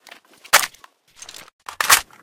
aug_a3_reload.ogg